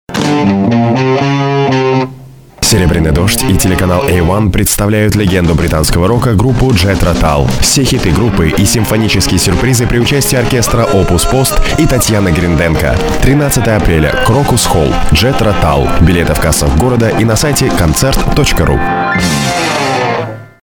Тракт: продакшн студия радиостанции. продакшн студия рекламного агентства домашний продакшн mic AKG, audio - Lexicon, Sony SF, Cubase